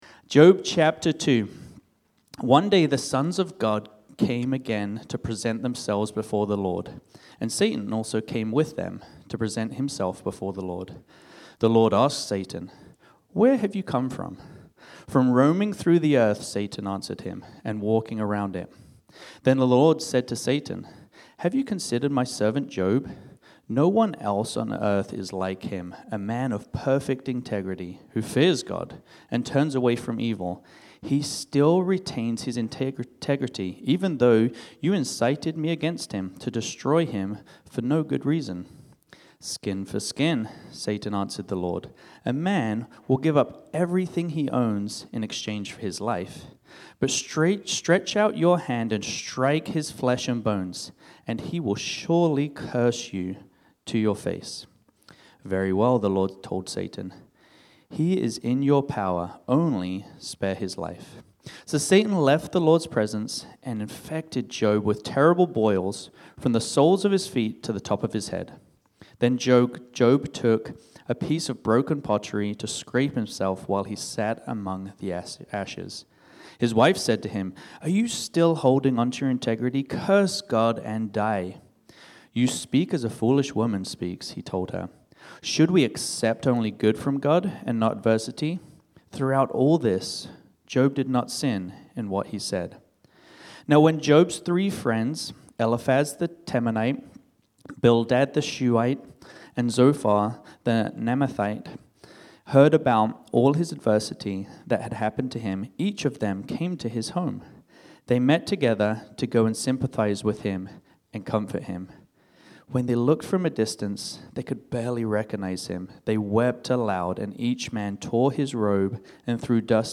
This sermon was originally preached on Sunday, January 11, 2026.